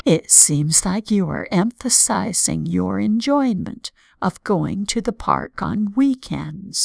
stress2_Munching_1.wav